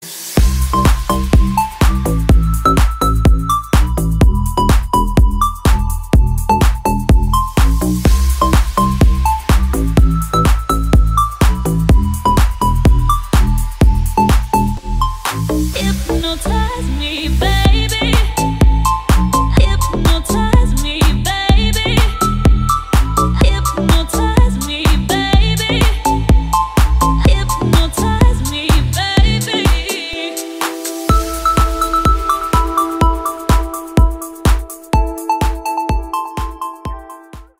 deep house
атмосферные
EDM
красивая мелодия
красивый женский голос